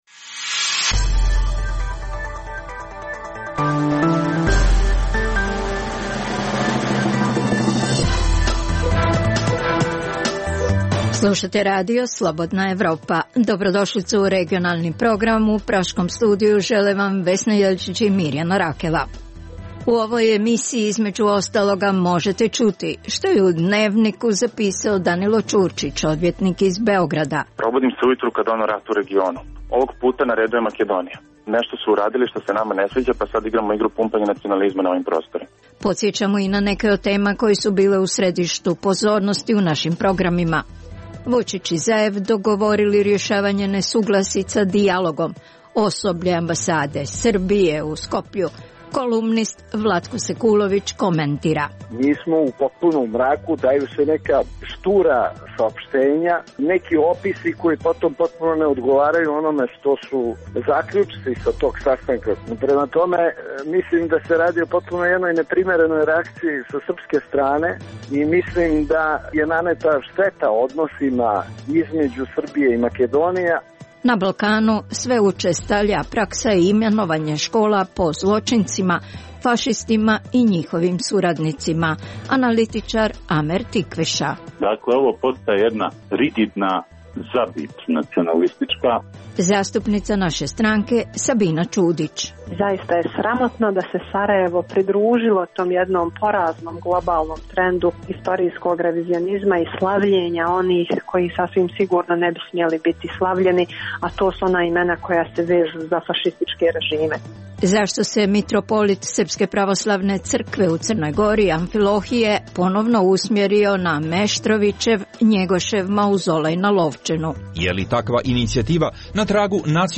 u kojem ugledni sagovornici iz regiona razmtraju aktuelne teme. Drugi dio emisije čini program "Pred licem pravde" o suđenjima za ratne zločine na prostoru bivše Jugoslavije.